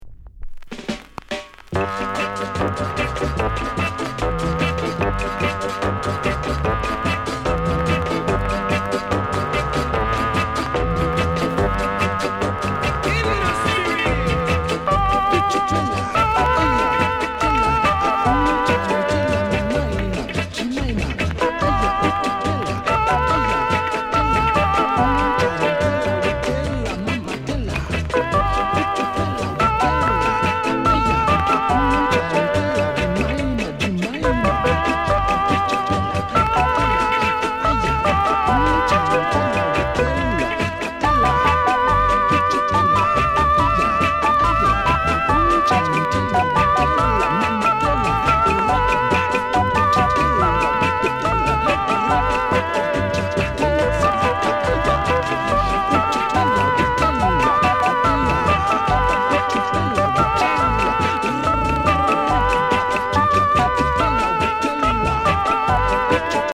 NICE SKINHEAD INST